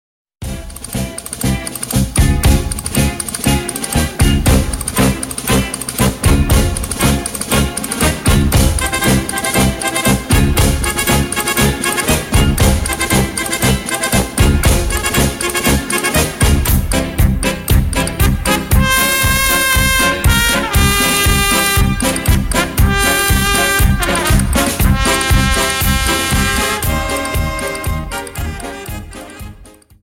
Paso Doble 59 Song